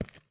High Quality Footsteps
STEPS Concrete, Run 22, Long Ending.wav